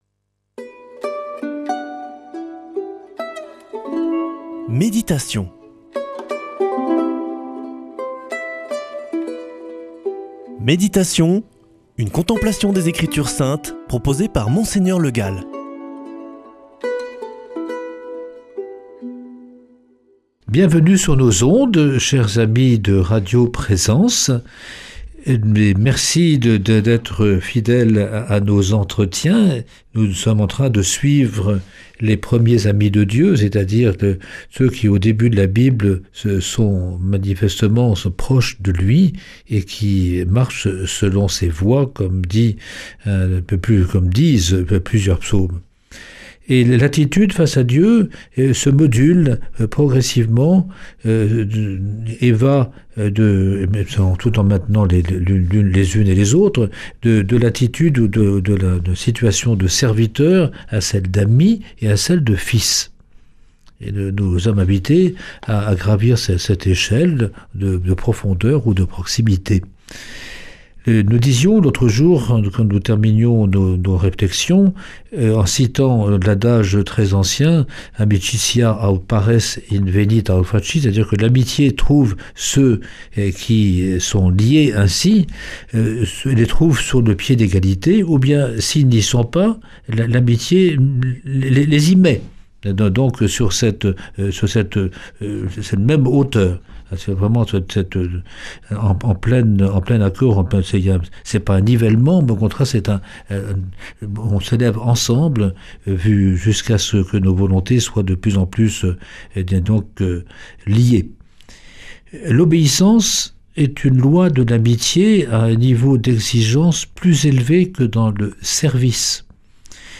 Méditation avec Mgr Le Gall
Une émission présentée par
Présentateur